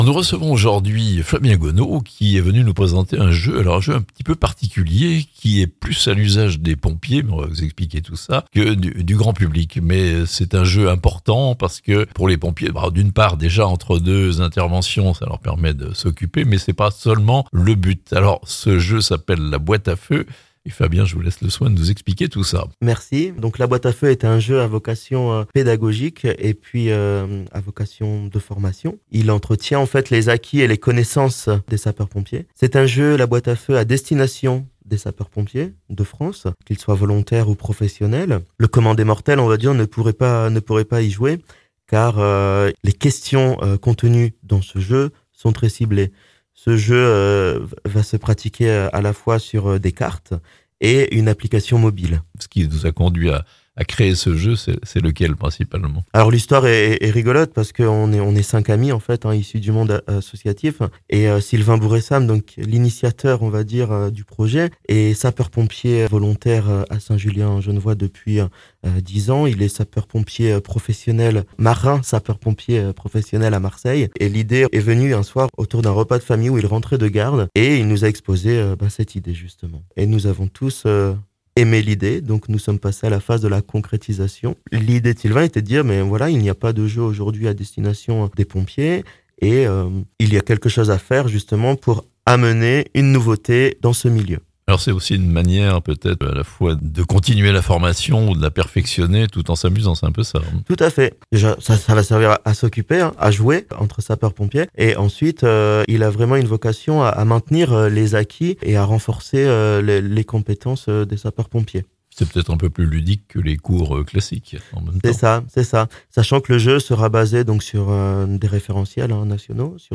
Un jeu de société destiné aux sapeurs-pompiers (Interview)